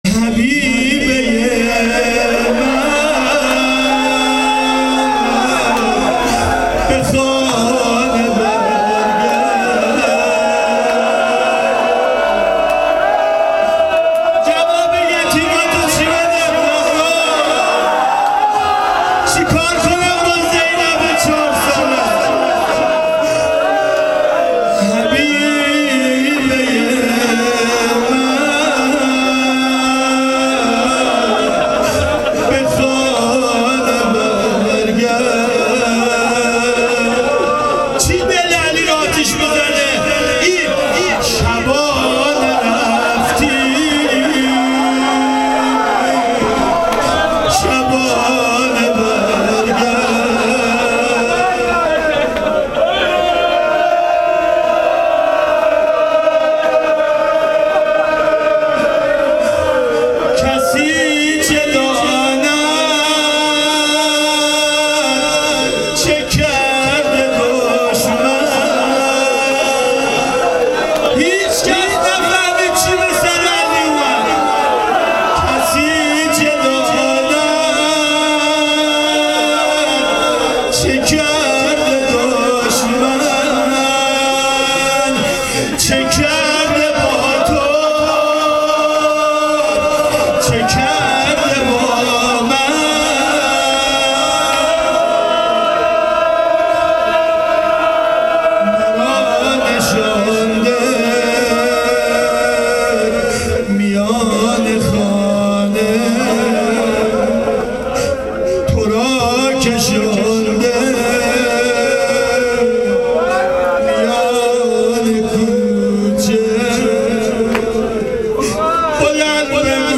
آرشیو ایام فاطمیه